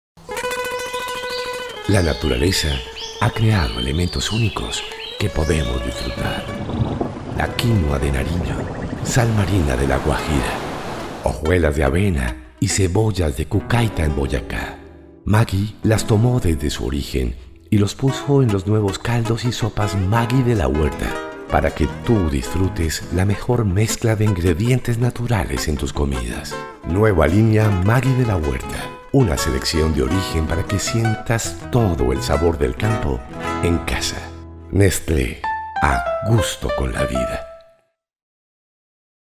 I can perform corporate, serious, deep and friendly voices
I am a professional broadcaster, I speak neutral Spanish, I am from Colombia.
kolumbianisch
Sprechprobe: Industrie (Muttersprache):